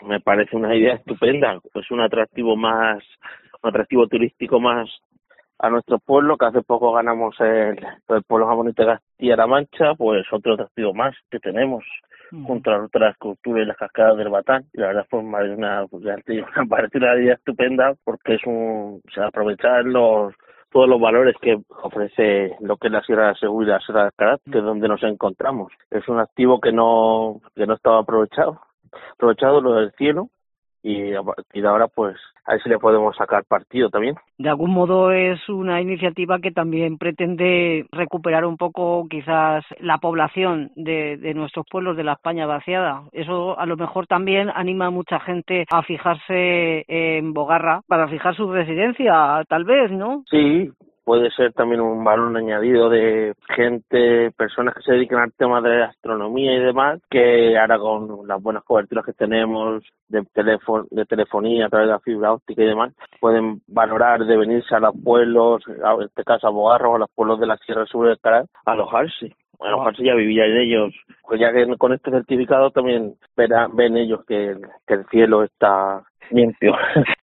Recientemente elegida como pueblo más bonito de Castilla La Mancha, su alcalde, Andrés Carreño se siente orgulloso por ello, y nos cuenta, que la iniciativa de Diputación ha sido muy bien acogida, ya que supone un aliciente más para atraer visitantes, y quién sabe si futuros habitantes cautivados por sus encantos paisajisticos , y su tranquilidad.( entrevista Andrés Carreño )